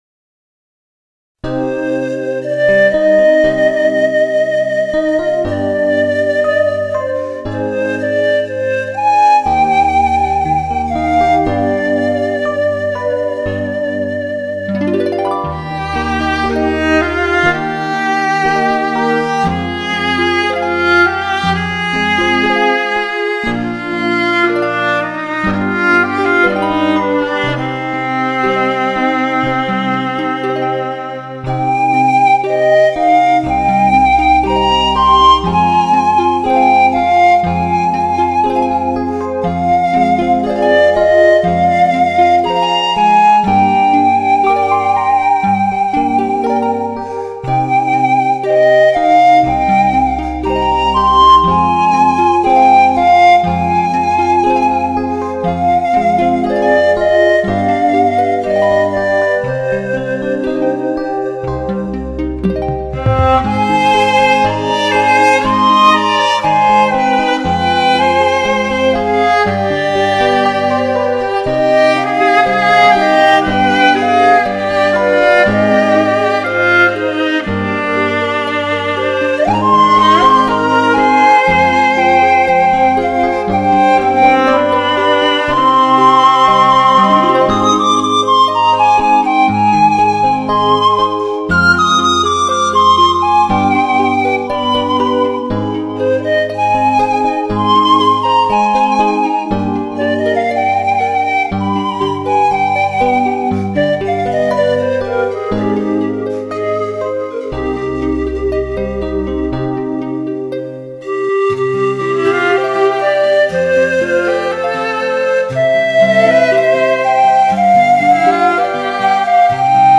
悦耳动听的旋律妙韵，让你在这世外桃源里享受与世无争，
音色柔和、深沉，略带些沙哑，独具特色。
感情丰富细腻的演奏，让你感受音乐飘逸的魅力及不食人间烟火的美感！